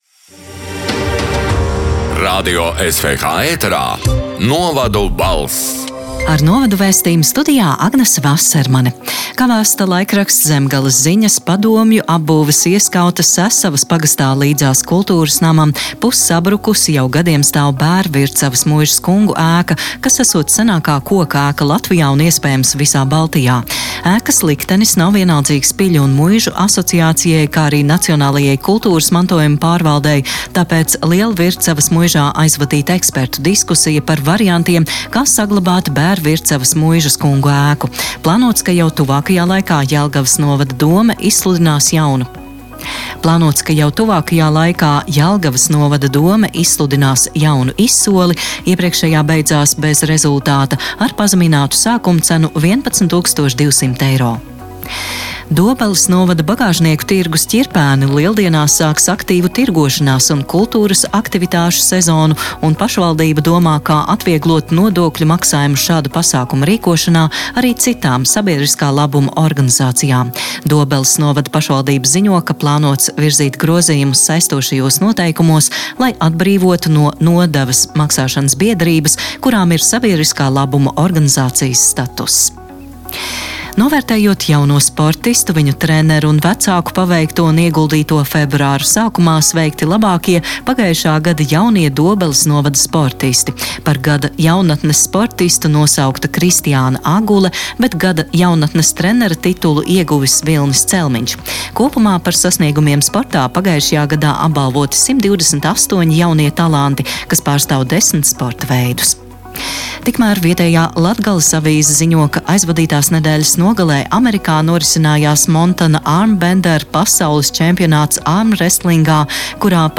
“Novadu balss” 14. februāra ziņu raidījuma ieraksts: